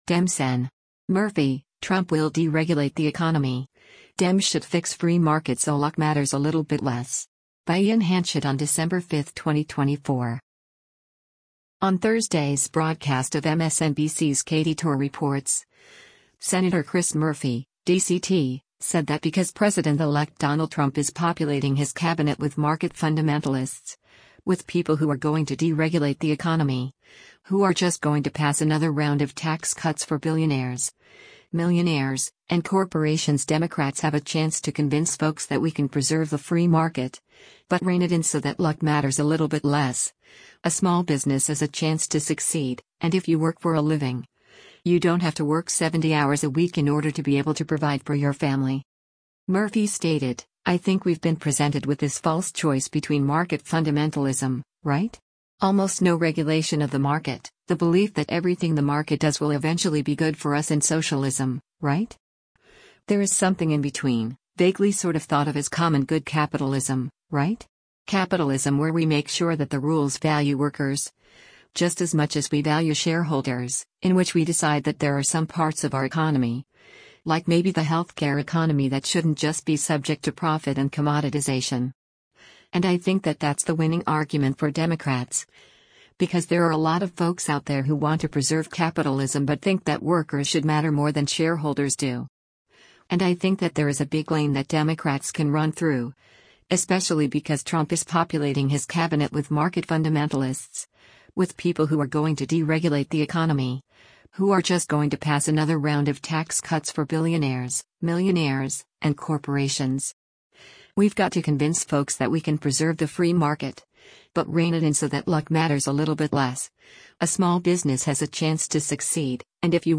On Thursday’s broadcast of MSNBC’s “Katy Tur Reports,” Sen. Chris Murphy (D-CT) said that because President-Elect Donald Trump “is populating his Cabinet with market fundamentalists, with people who are going to deregulate the economy, who are just going to pass another round of tax cuts for billionaires, millionaires, and corporations” Democrats have a chance to “convince folks that we can preserve the free market, but rein it in so that luck matters a little bit less, a small business has a chance to succeed, and if you work for a living, you don’t have to work 70 hours a week in order to be able to provide for your family.”